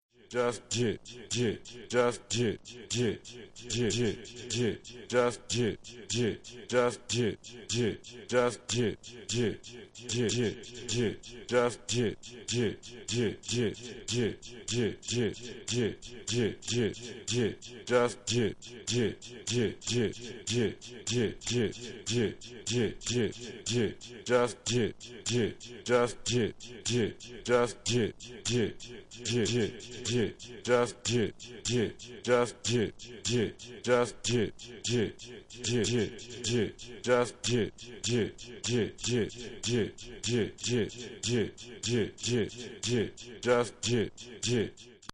Jittin' ghetto tracks..
Electro